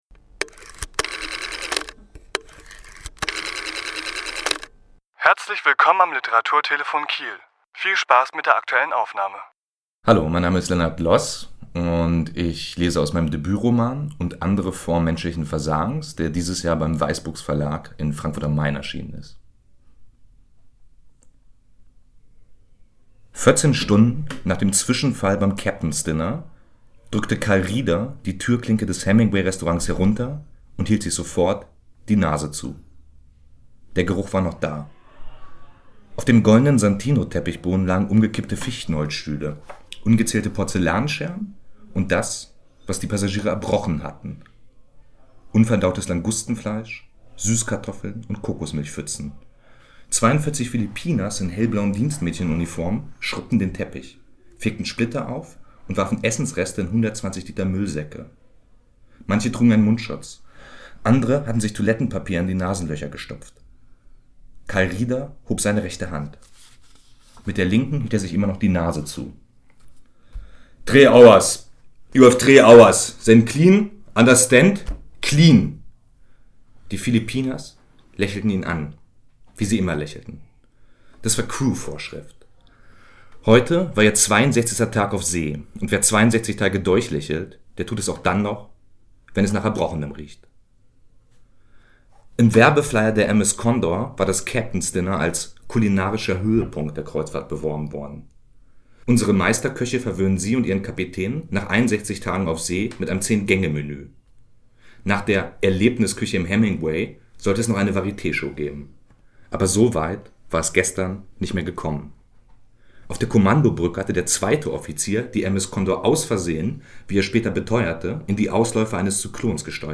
Autor*innen lesen aus ihren Werken
Die Aufnahme entstand im Rahmen der LeseLounge im Literaturhaus SH (veranstaltet vom Jungen Literaturhaus SH mit freundlicher Unterstützung der Investitionsbank SH und des Freundeskreises des Literaturhauses SH) am 19.6.2019.